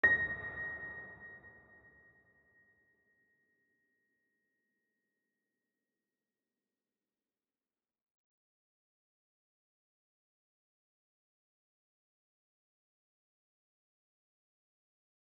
piano-sounds-dev
GreatAndSoftPiano